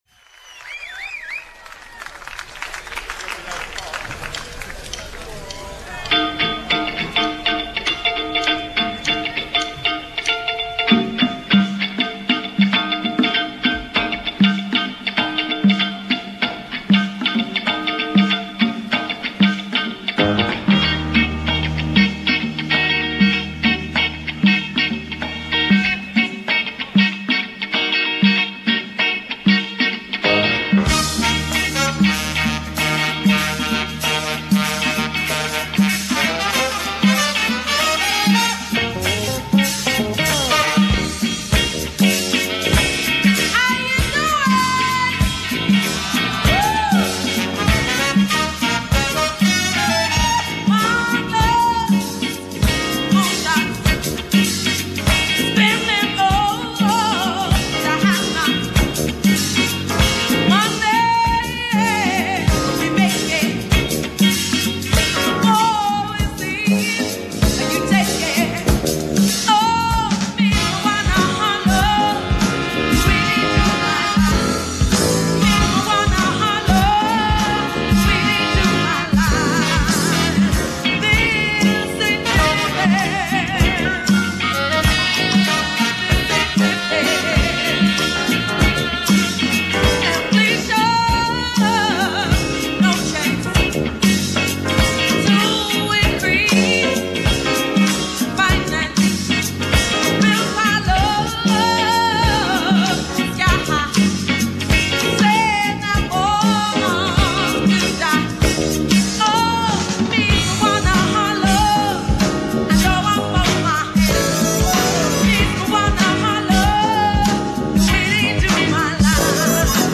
In Concert At Glastonbury
guitarist
Sax player
Bringing Jazz-Funk to the mix.